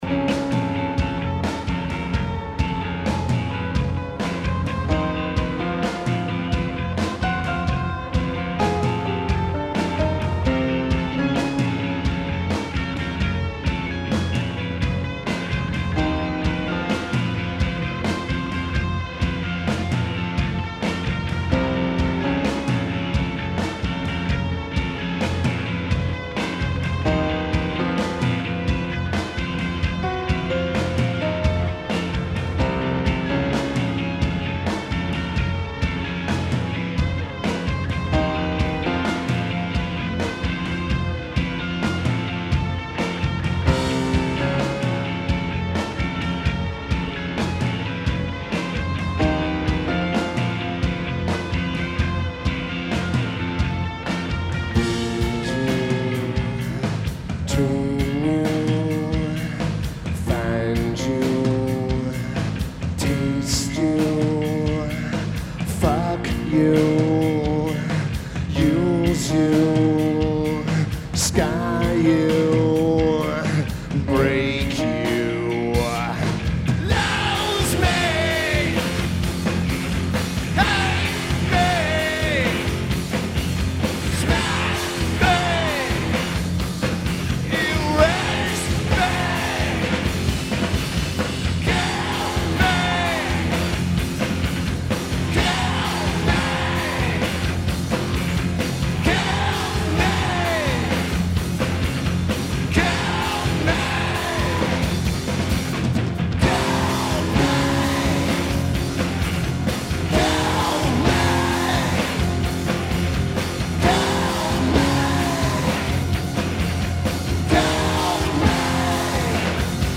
Aragon Ballroom
Drums
Bass
Guitar
Vocals/Guitar/Keyboards
Lineage: Audio - SBD